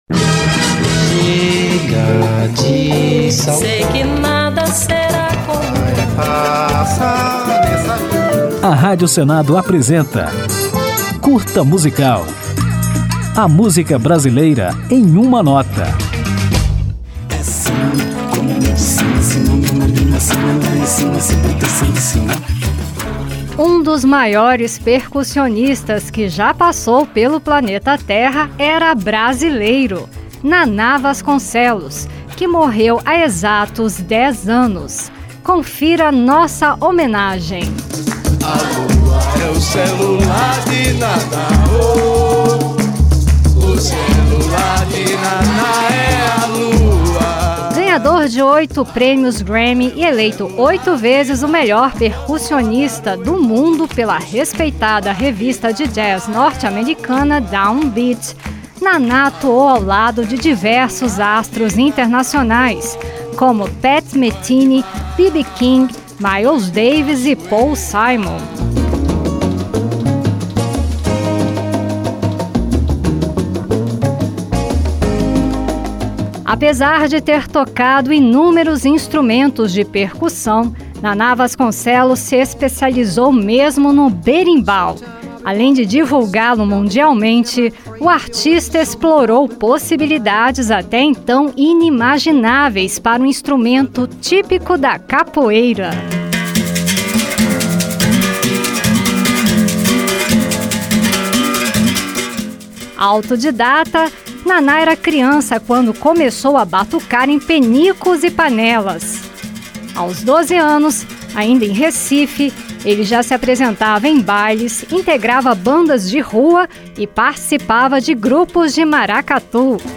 Ganhador de oito prêmios Grammy e eleito oito vezes o melhor percussionista do mundo, o músico pernambucano fez história ao explorar diferentes possibilidades para o berimbau. Em homenagem, o Curta Musical preparou um especial sobre o artista, que termina ao som da música Futebol, lançada por Naná Vasconcelos no disco Bush Dance de 1986.